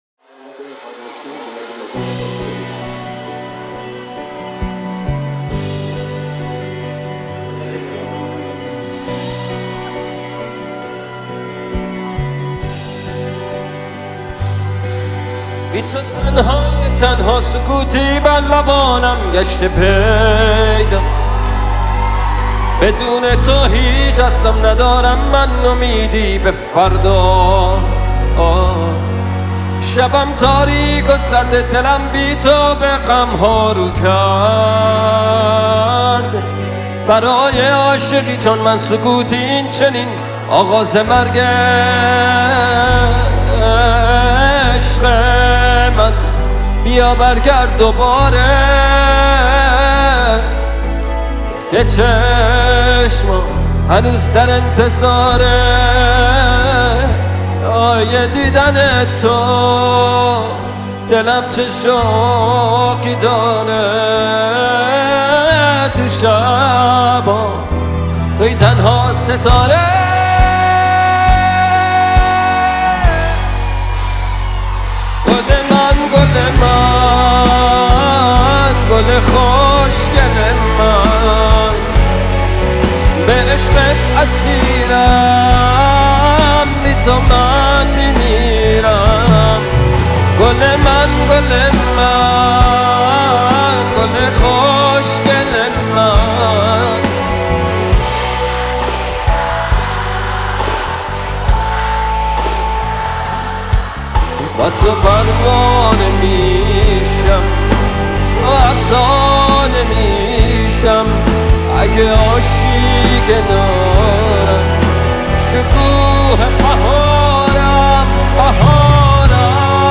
موسیقی پاپ فارسی